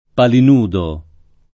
[ palin 2 do ]